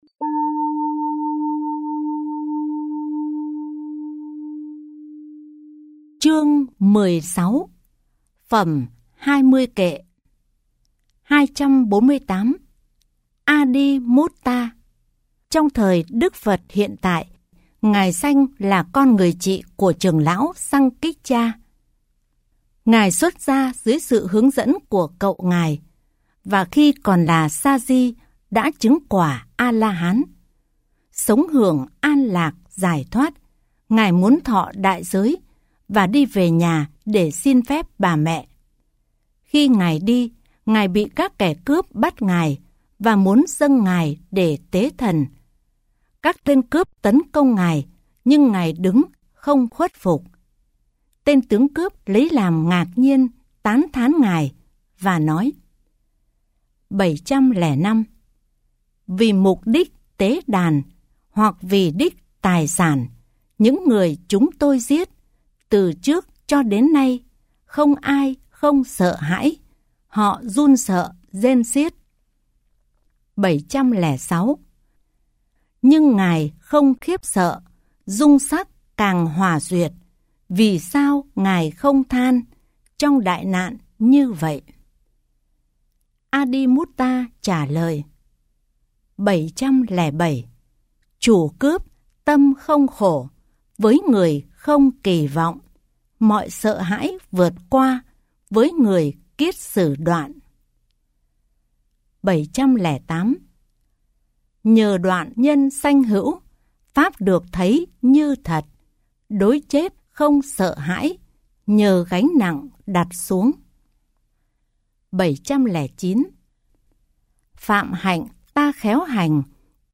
30 K Tiểu Bộ tập 2 – Trưởng lão Tăng kệ – Chương 16 – Phẩm Hai Mươi Kệ giọng miền Bắc.mp3